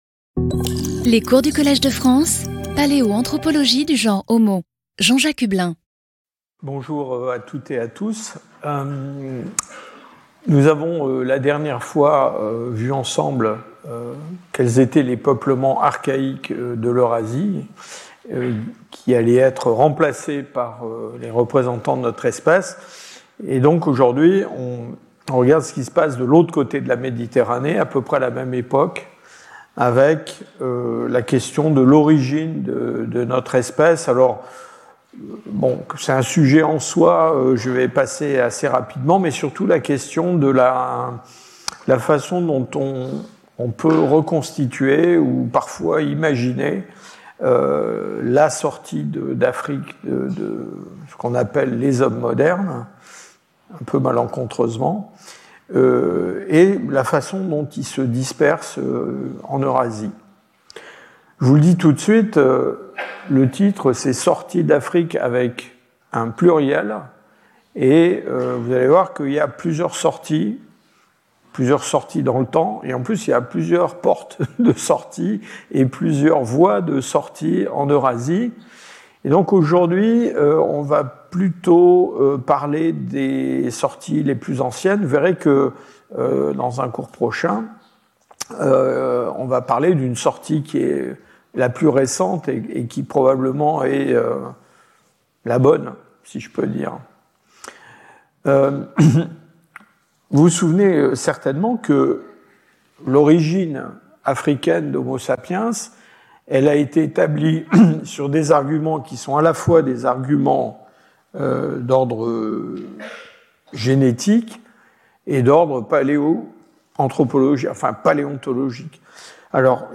Jean-Jacques Hublin Professeur du Collège de France
Cours